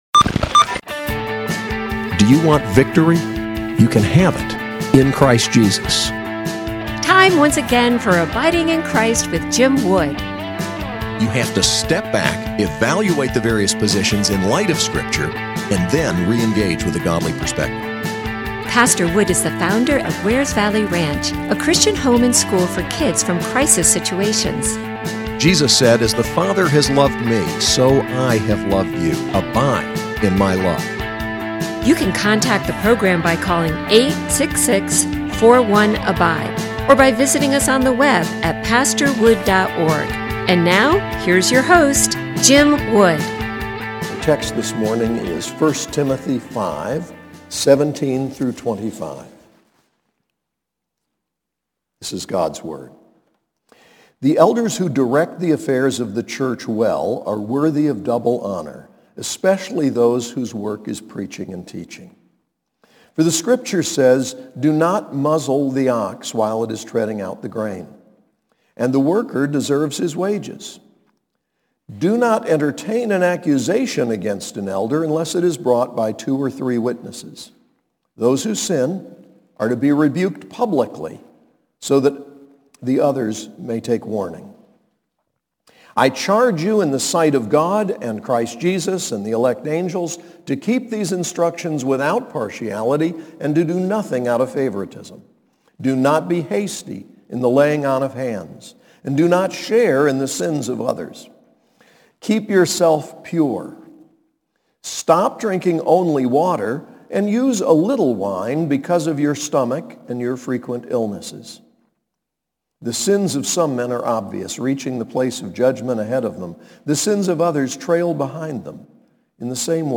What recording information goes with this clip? SAS Chapel: 1 Timothy 5:17-25